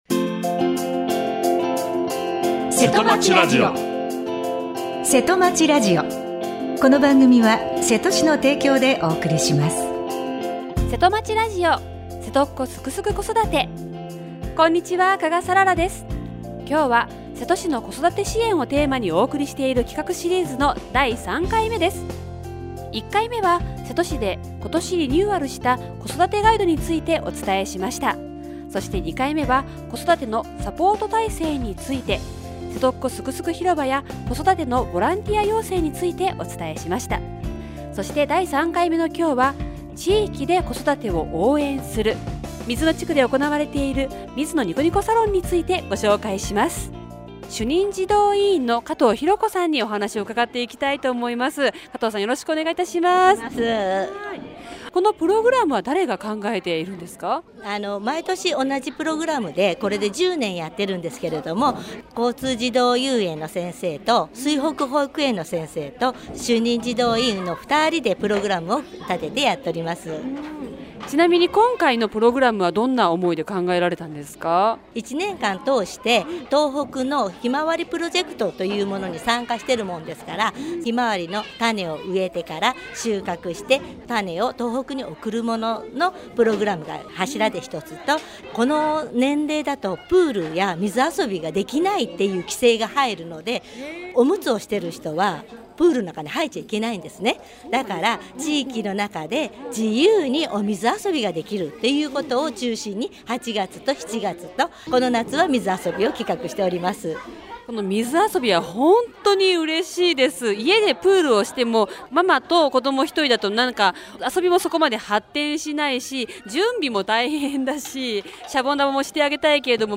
28年8月23日（火） 毎週火曜日は せと教えてQ&A 今日は「せとっ子すくすく子育て支援」企画シリーズの3回目をお送りします。 第３回目は、８月８日（月）に水野の「下水道展示館」で行われた、 水野地区の「地域子育てサロン」を取材した模様をお送りします。